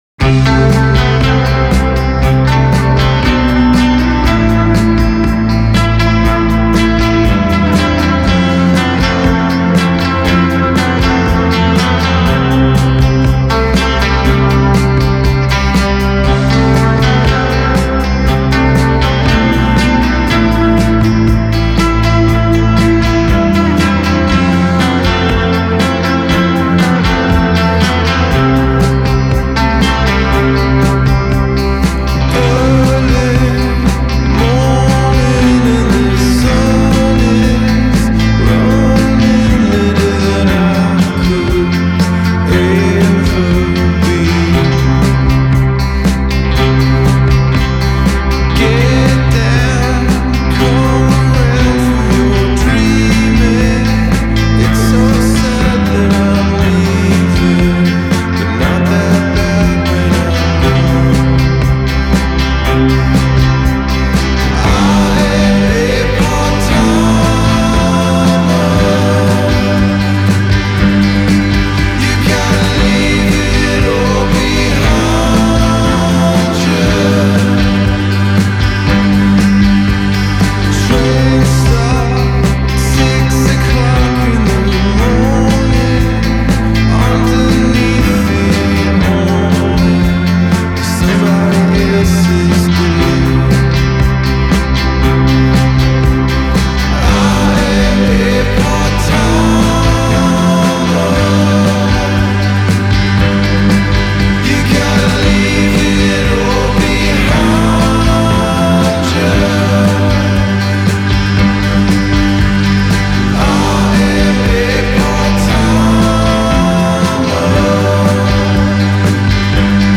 Genre: Indie Pop, Rock, Alternative